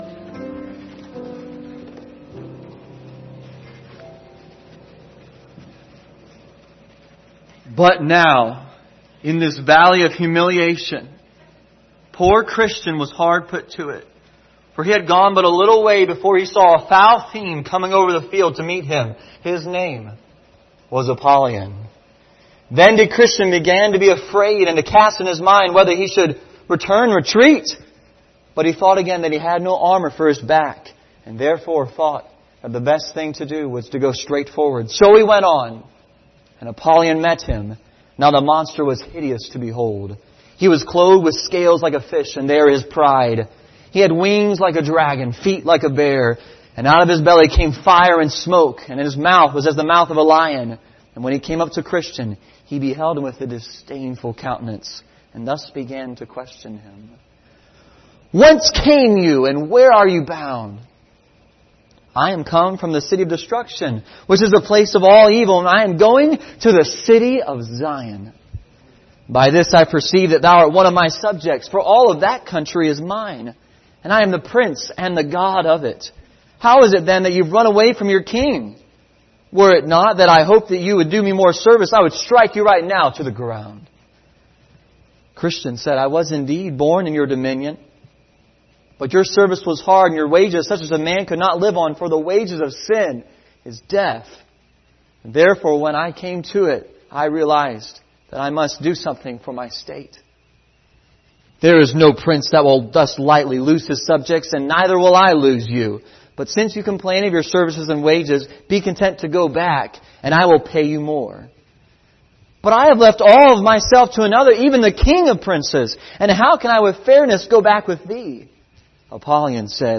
Passage: Ephesians 6:10-16 Service Type: Sunday Morning